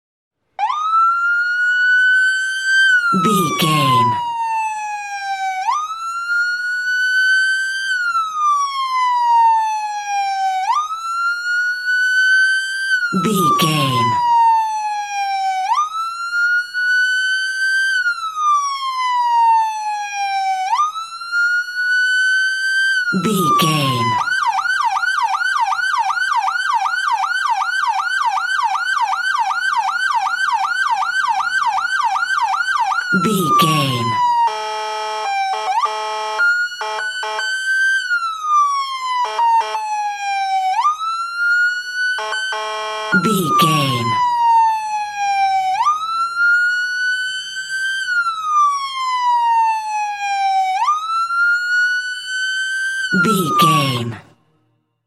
Police Car Large Short Siren Horn Combo 122
Sound Effects
urban
chaotic
dramatic